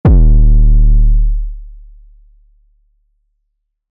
bass-drop.MP3